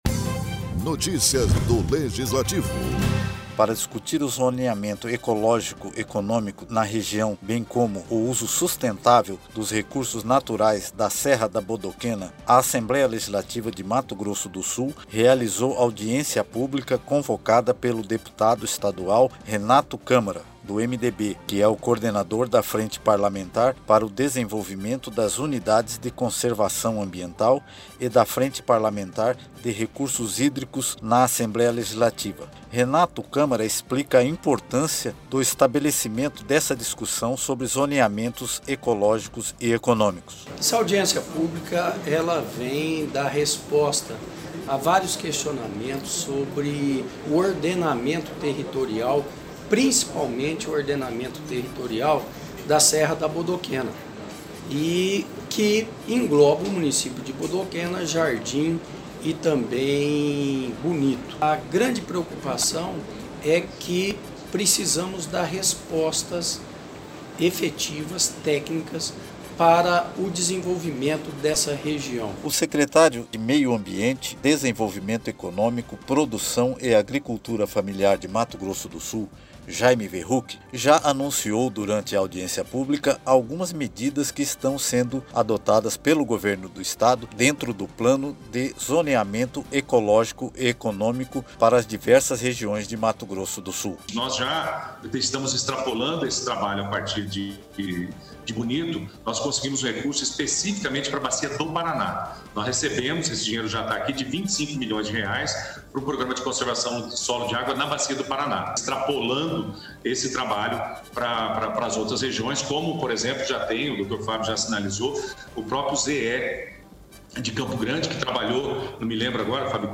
Renato Câmara explica a importância o estabelecimento desta discussão.